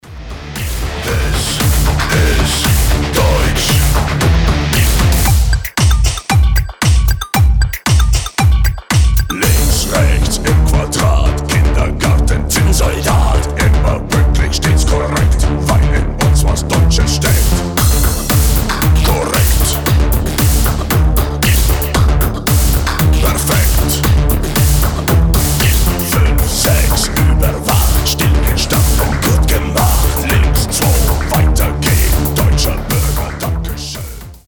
• Качество: 320, Stereo
мощные
брутальные
эпичные
Industrial metal